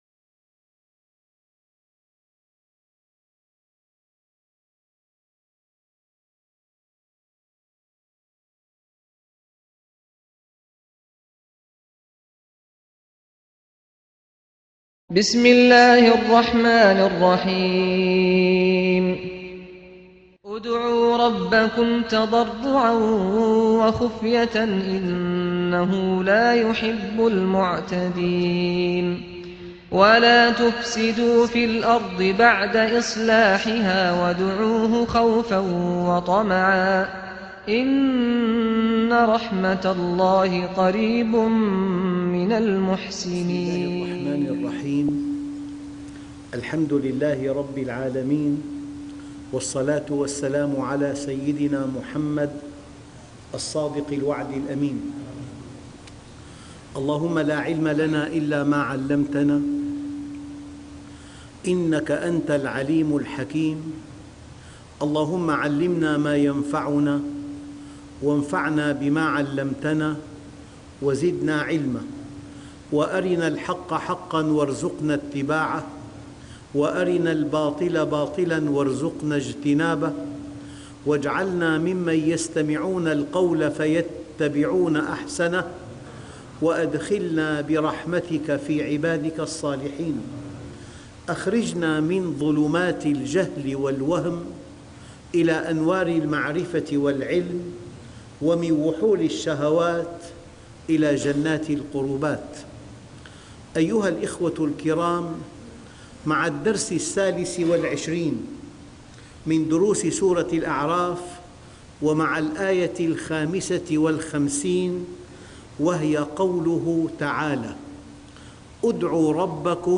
الدرس (23) تفسير سورة الأعراف - الشيخ محمد راتب النابلسي